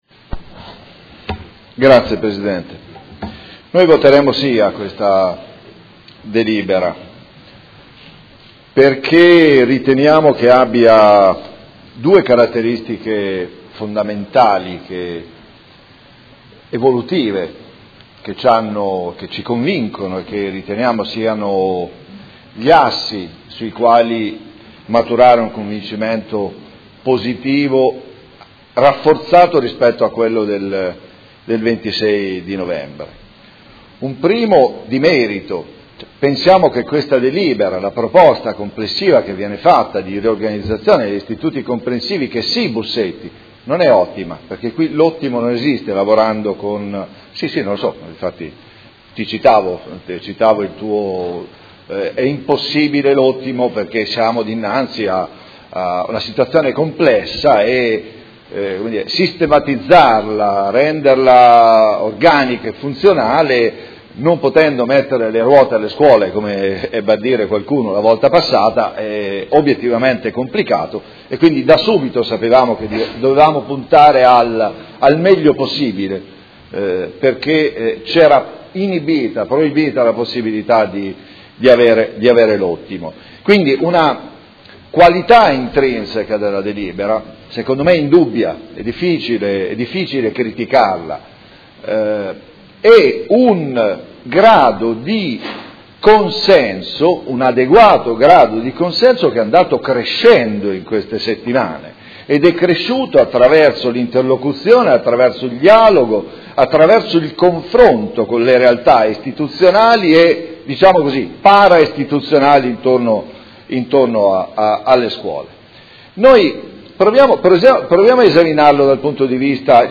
Seduta del 14/01/2016. Dichiarazione di voto su delibera, emendamento e ordine del giorno riguardanti gli Istituti Comprensivi